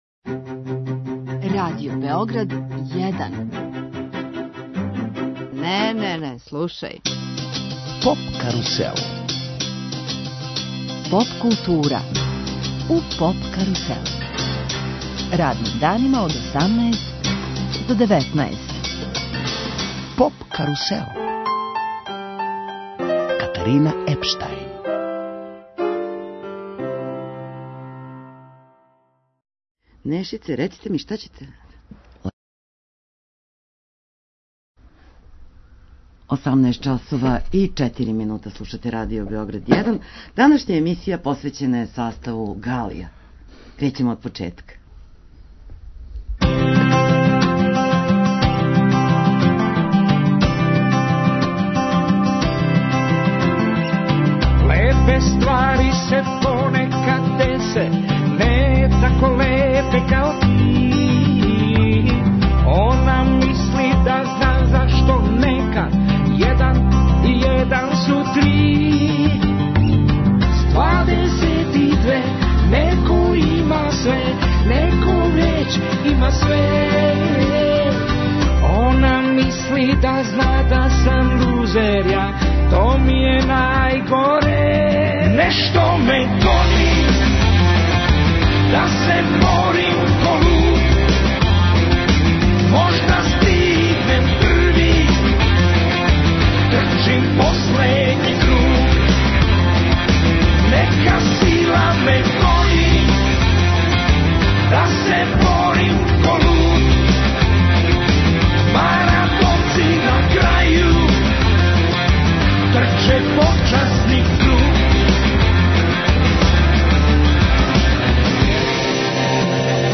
Емисија је посвећена саставу Галија, поводом предстојећег концерта. Гост емисије је оснивач Ненад Милосављевић, познатији као Неша Галија.